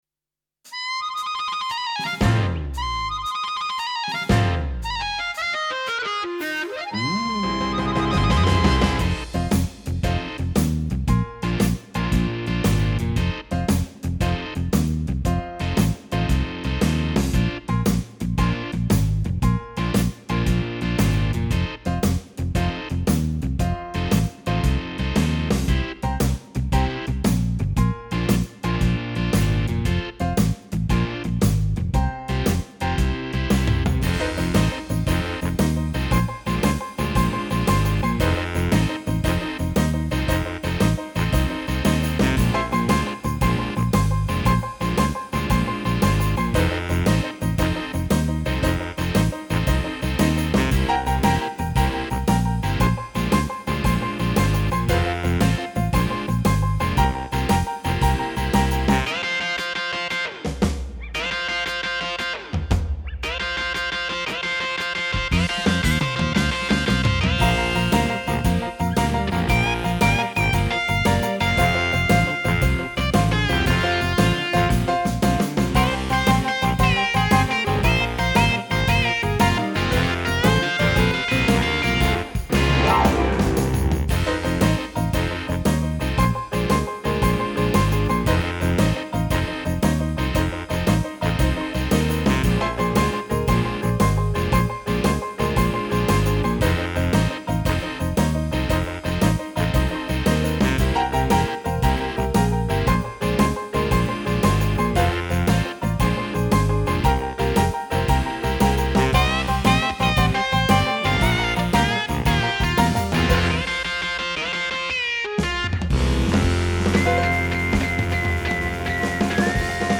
Home > Music > Blues > Bright > Smooth > Medium